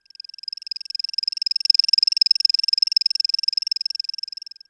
Machine01.wav